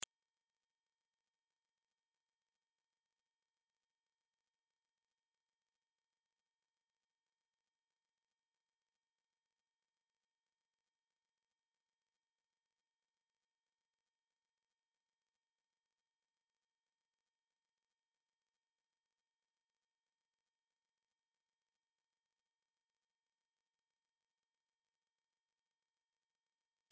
Звуки отпугивания комаров
Подборка включает различные частоты и тональности, доказавшие свою эффективность против мошек и комаров.
Тихий звук против комаров